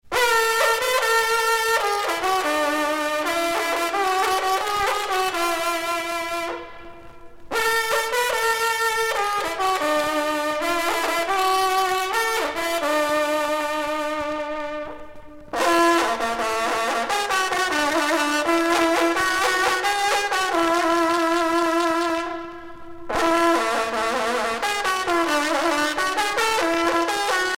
sonnerie vénerie - fanfare d'équipage